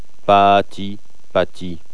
Endico la silabo acentuado. Eis. : ti, pati
pati.wav